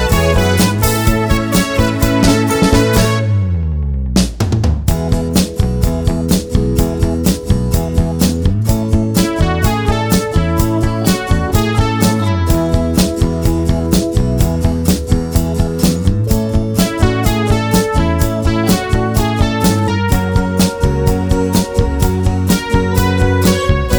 no Backing Vocals Soul / Motown 3:39 Buy £1.50